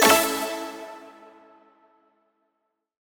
confirm-selection.wav